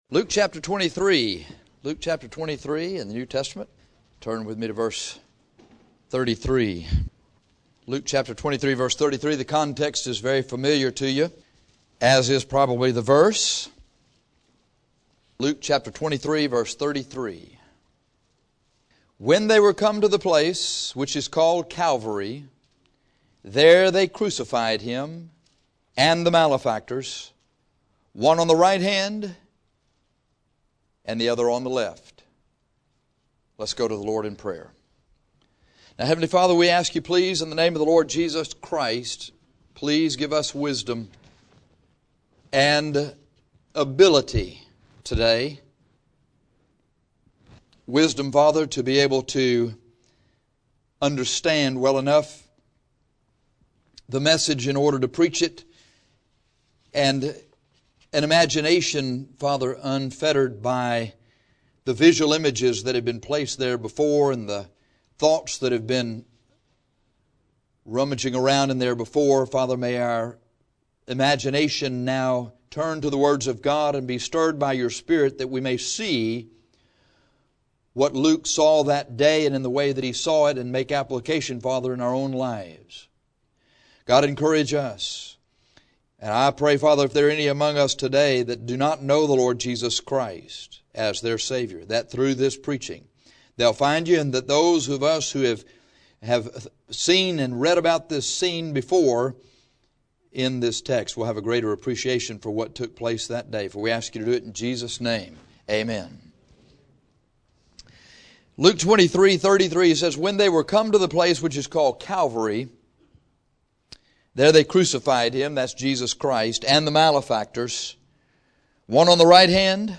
The Three Crosses - Bible Believers Baptist Church | Corpus Christi, Texas